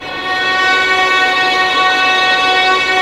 Index of /90_sSampleCDs/Roland LCDP13 String Sections/STR_Violins FX/STR_Vls Pont wh%